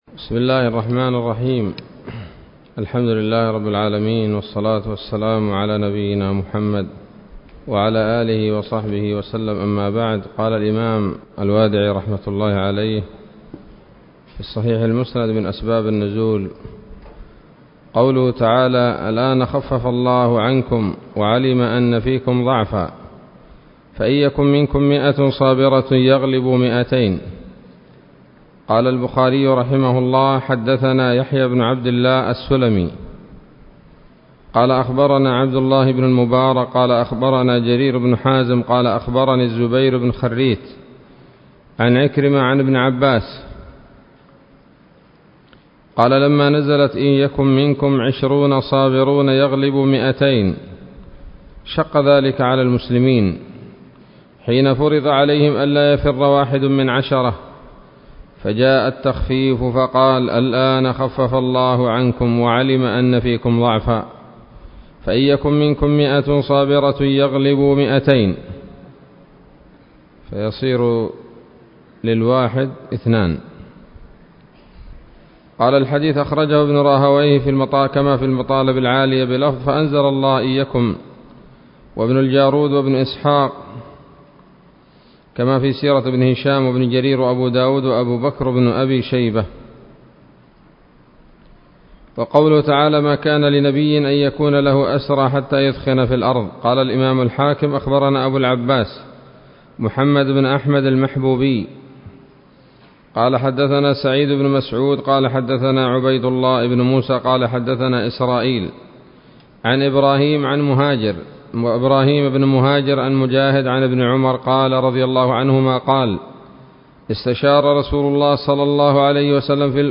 الدرس الحادي والأربعون من الصحيح المسند من أسباب النزول